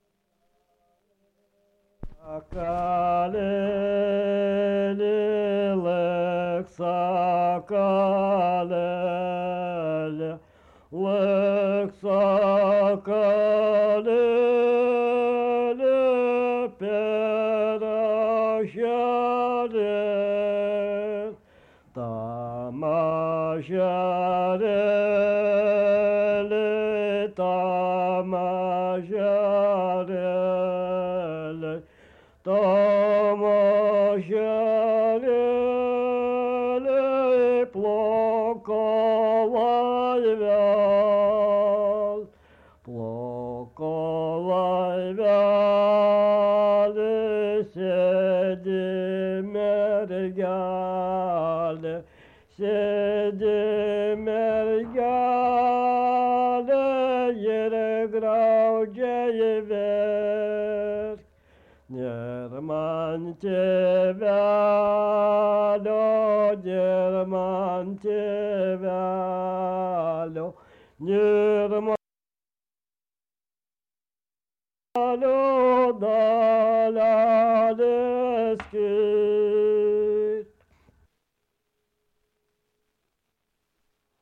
Tipas daina Erdvinė aprėptis Varėna
Atlikimo pubūdis vokalinis